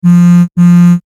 Phone Vibrating
Phone_vibrating.mp3